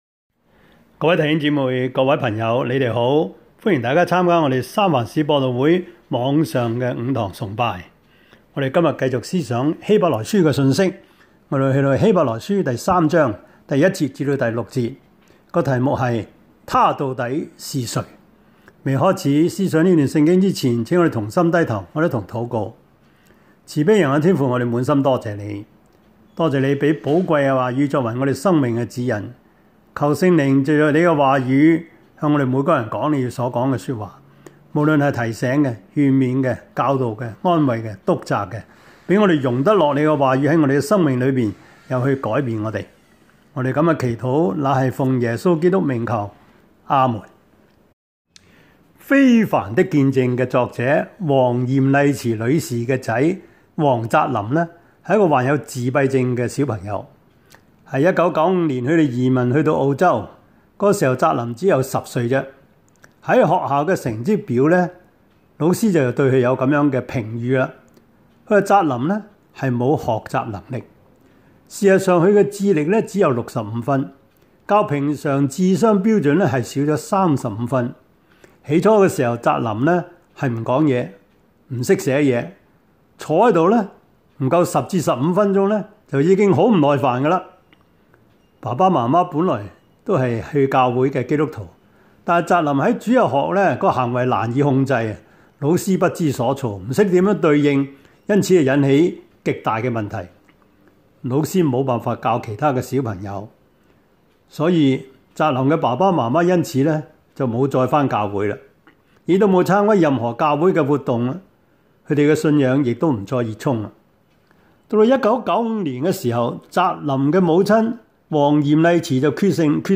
Service Type: 主日崇拜
Topics: 主日證道 « 萬物的結局近了 第六十一課:十九世紀 (美國) »